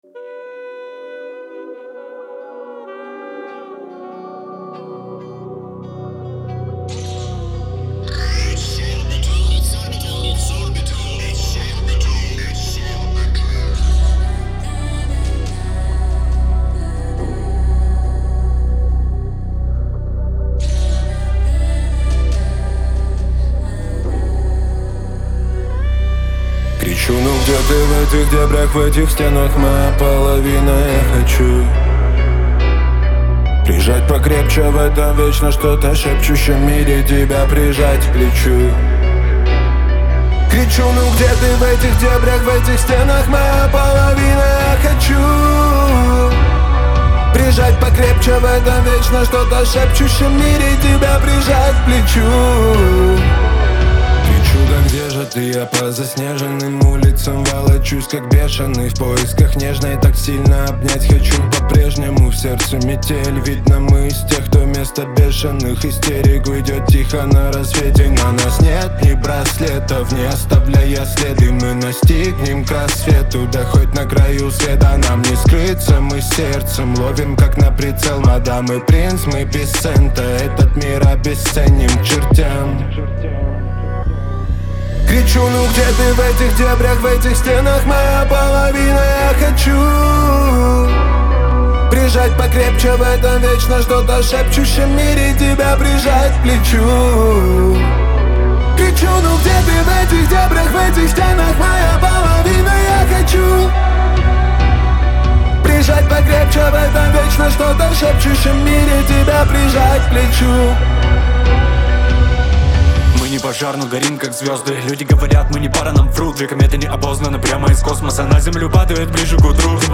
это трек в жанре поп